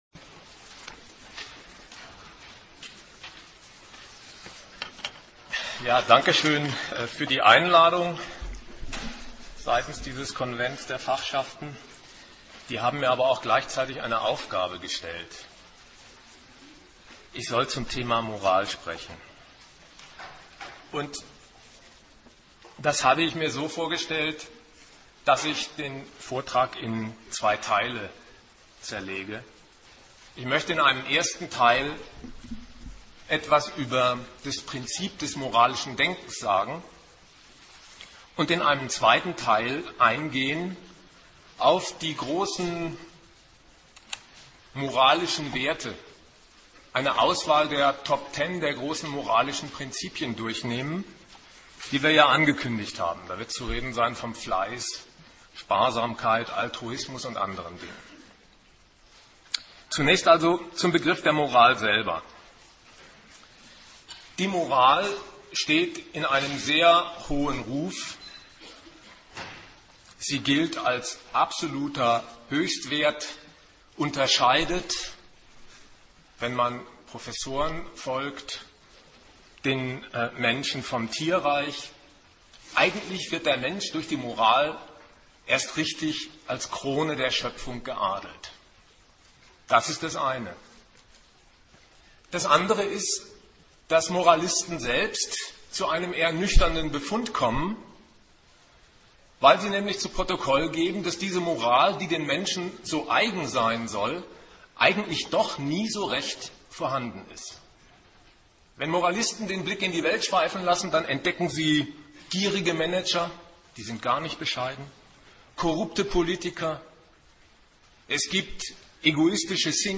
Der Vortrag will nicht nur den Begriff der Moral erläutern, sondern einmal die viel gepriesenen Tugenden höchstselbst auf den Prüfstand stellen.